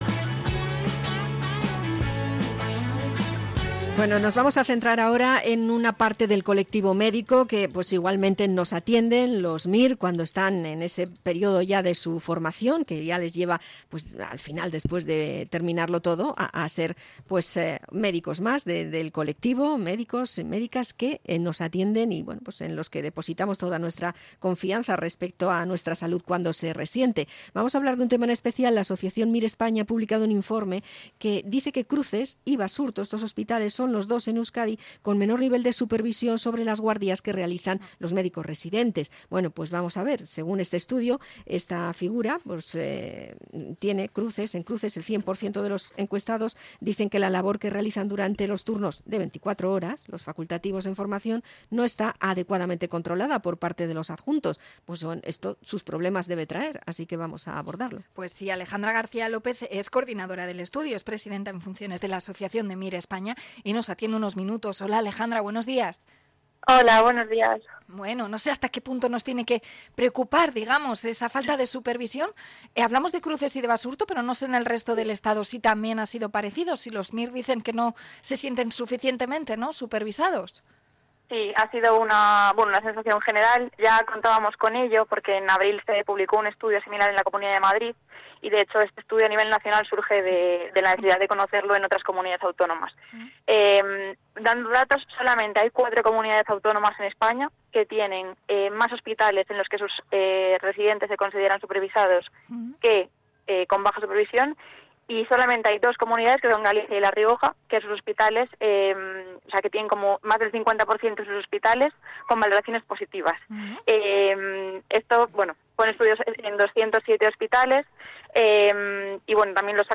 Entrevista a la Asociación MIR España